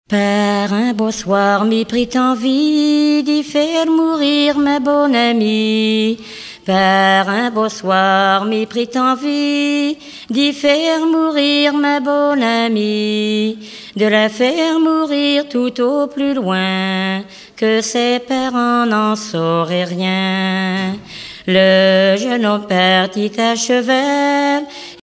Genre strophique
Chanteuse du pays de Redon